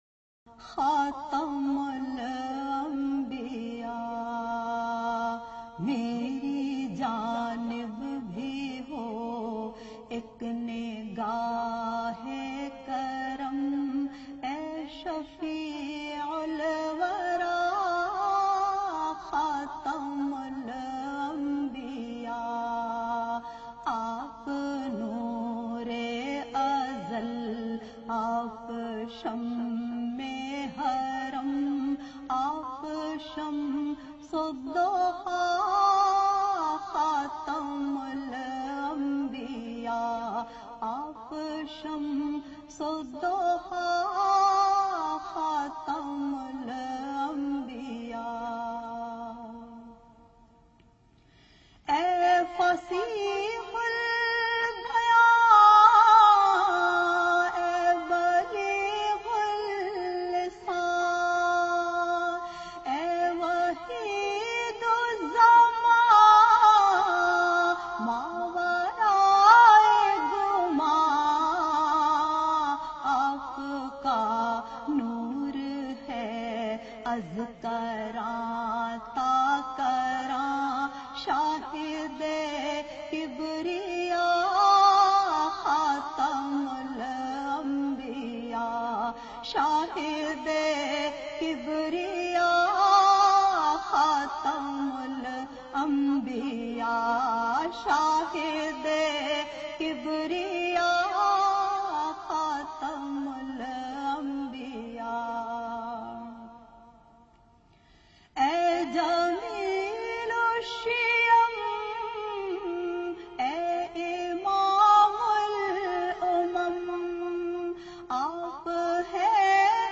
She has her very own style of reciting Naats.